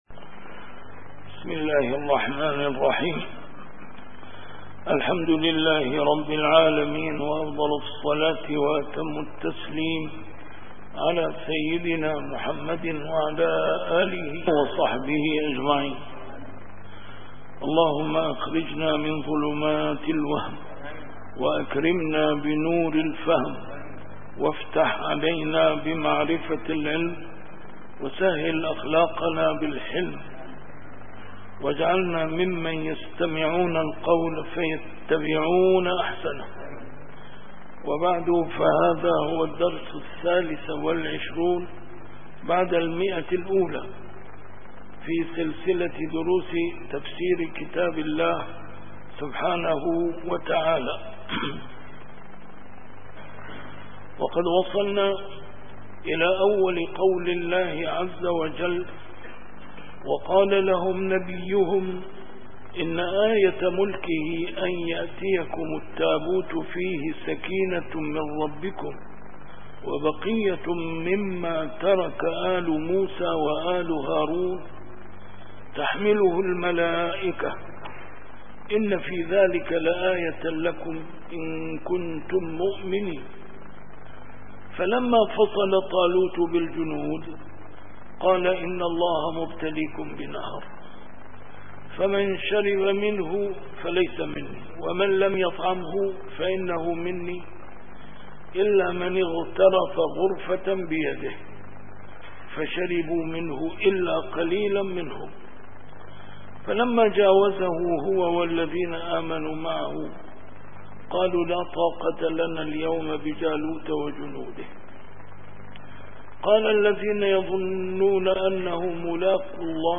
A MARTYR SCHOLAR: IMAM MUHAMMAD SAEED RAMADAN AL-BOUTI - الدروس العلمية - تفسير القرآن الكريم - تفسير القرآن الكريم / الدرس الثالث والعشرون بعد المائة: سورة البقرة: الآية 248-249